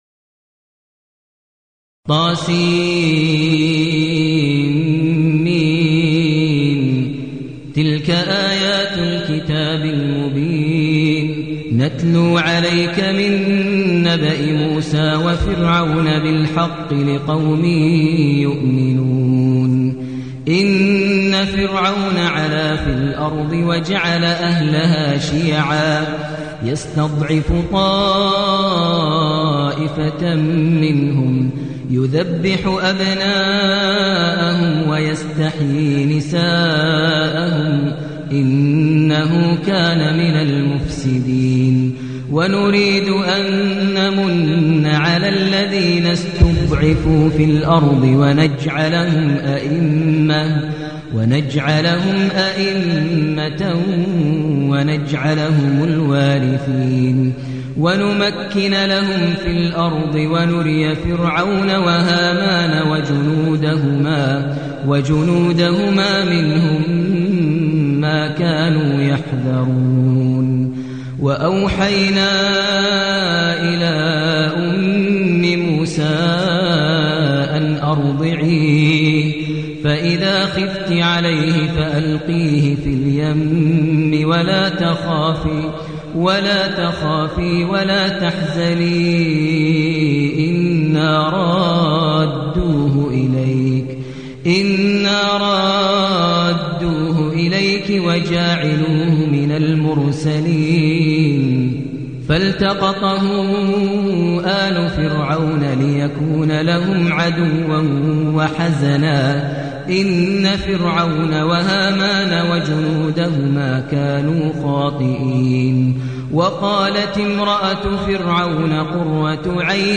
المكان: المسجد النبوي الشيخ: فضيلة الشيخ ماهر المعيقلي فضيلة الشيخ ماهر المعيقلي القصص